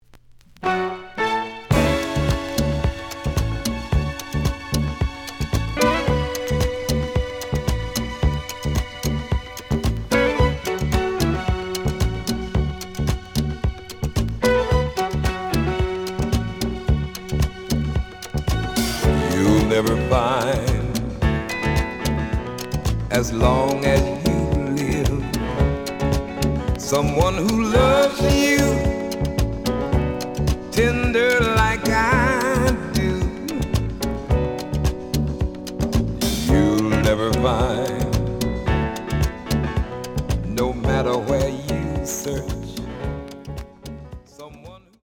The audio sample is recorded from the actual item.
●Genre: Soul, 70's Soul
Some damage on both side labels. Plays good.)